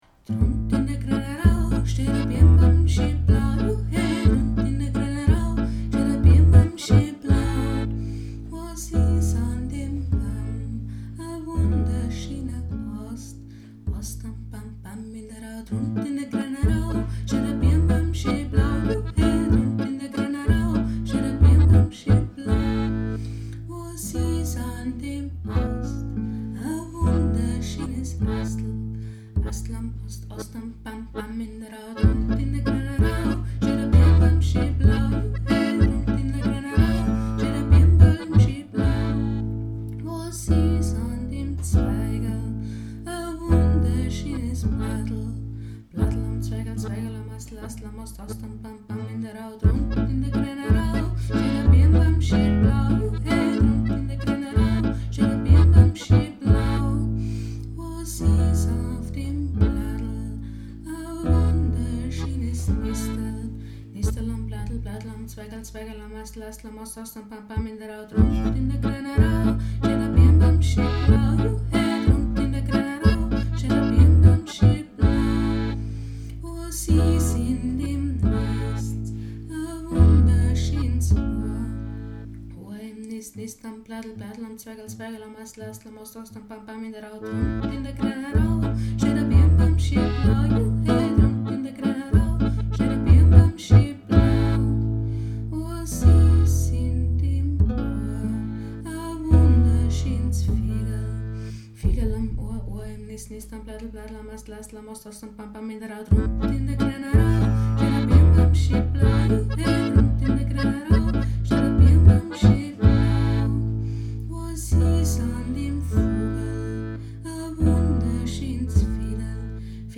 DRUNT IN DA GREANA AU - Liedeinspielung Eine Version mit weiteren Strophen gibt es unter diesem Link zu sehen.